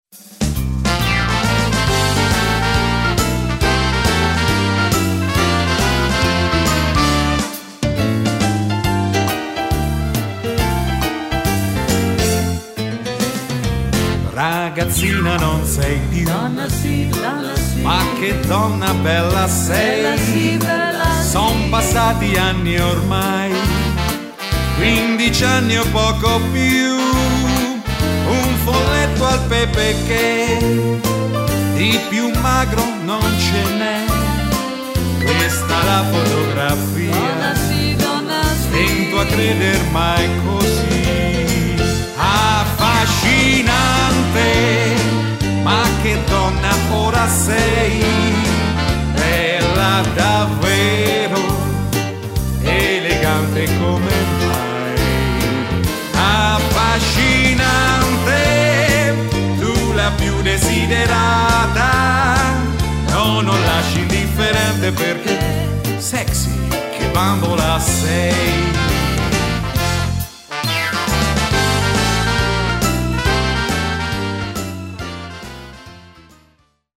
Fox trot
Uomo